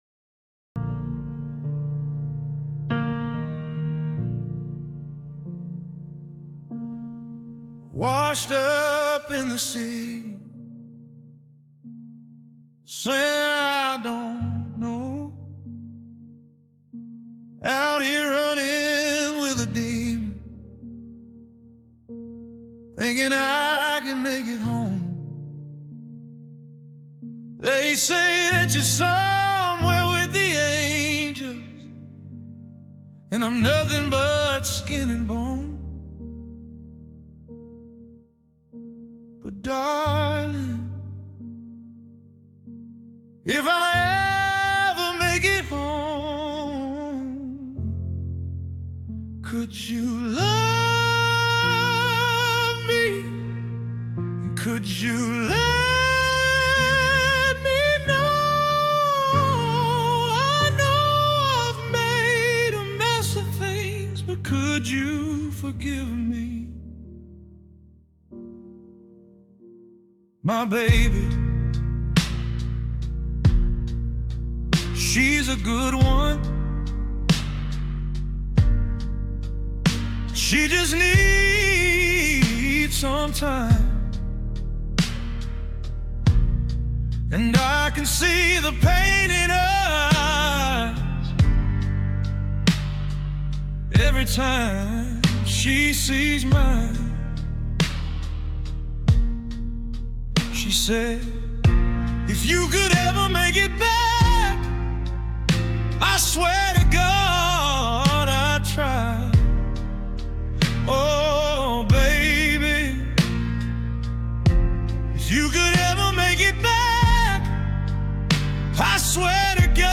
Official Studio Recording
a fusion of 140 bpm cinematic soul and gritty outlaw country